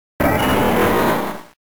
Archivo:Grito de Sceptile.ogg
== Licencia == {{Archivo de audio}} Categoría:Gritos de Pokémon de la tercera generación